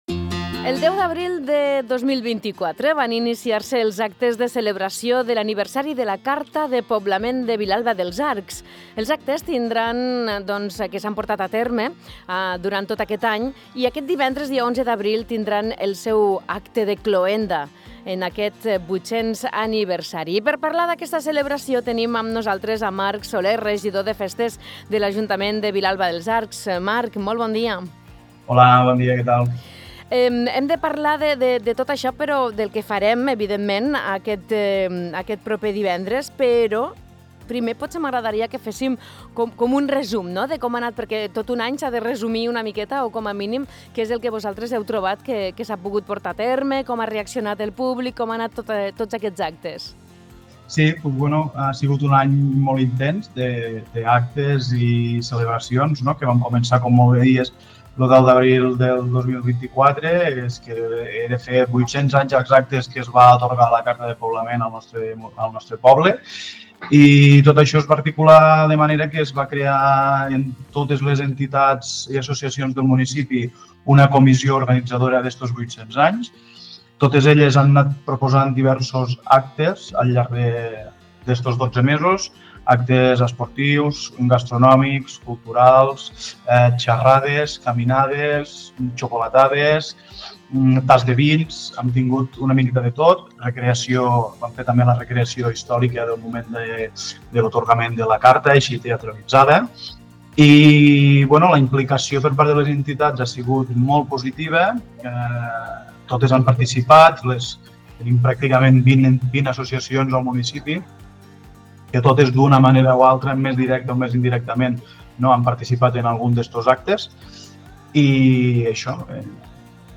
Marc Solé, regidor de festes de Vilalba dels Arcs, ens explica com s’ha viscut aquest aniversari i quins són els actes previstos per al divendres 11 d’abril, dia en què es durà a terme la cloenda d’aquesta commemoració tan significativa.
entrevista-marc-sole-vilalba.mp3